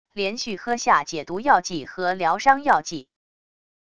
连续喝下解毒药剂和疗伤药剂wav音频